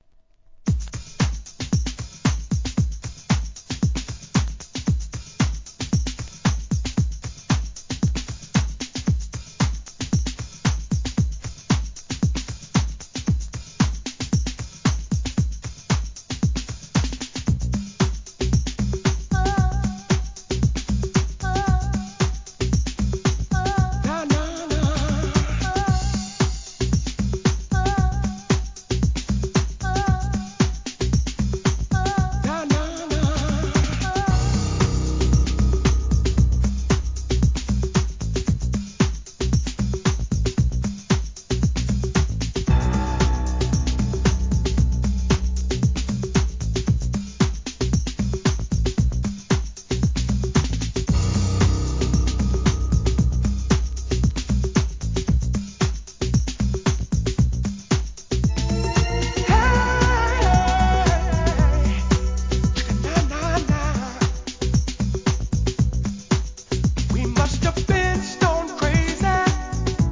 '90年代初頭大ヒットのポップダンス!!